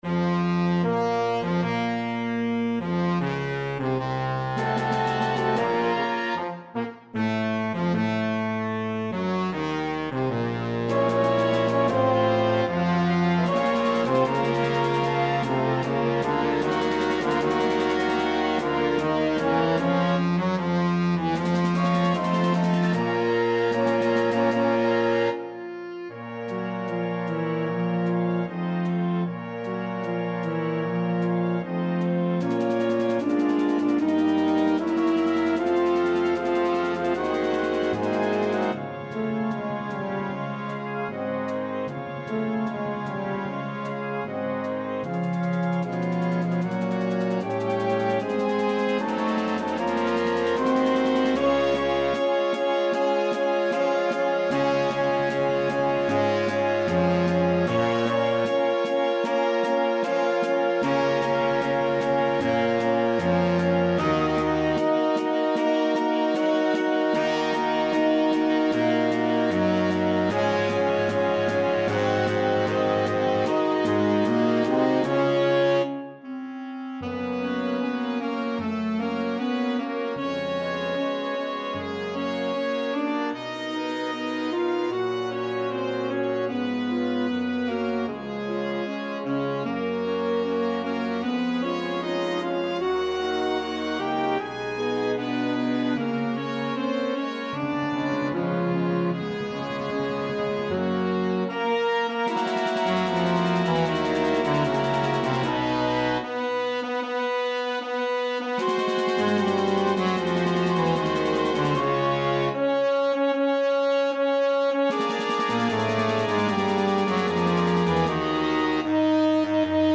Voicing: Concert March